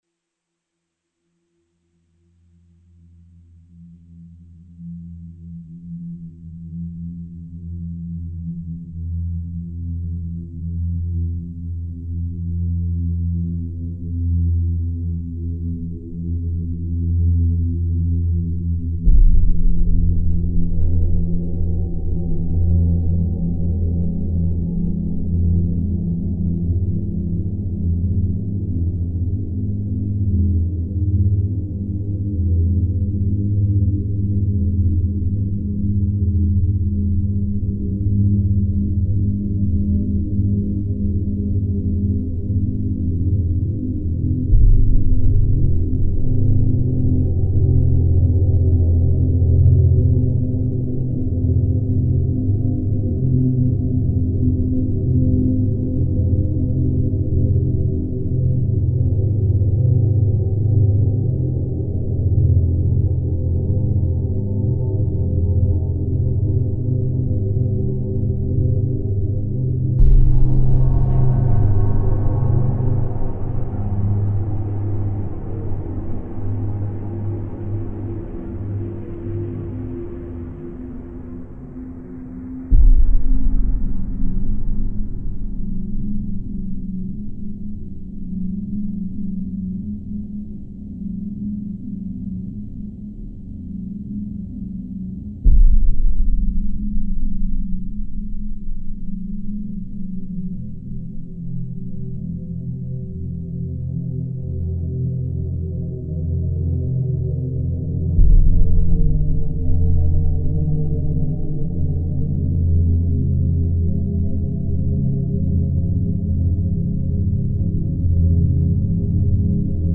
Scary ambo.